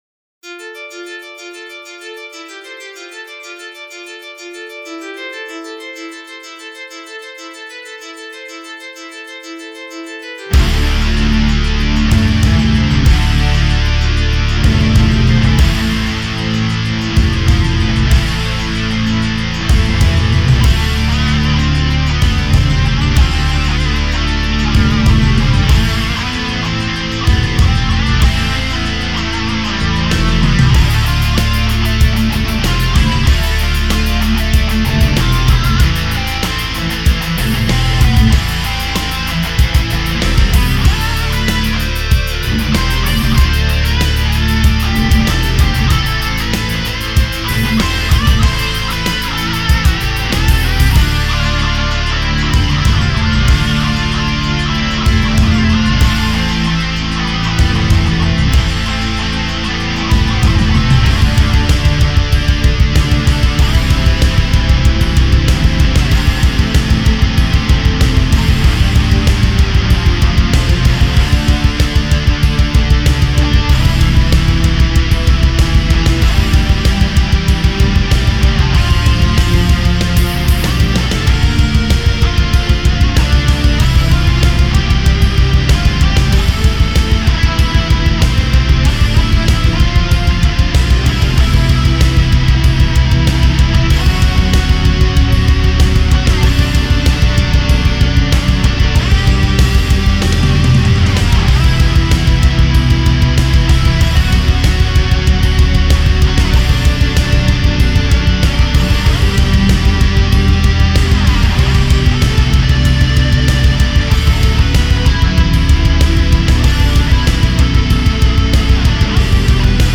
metal version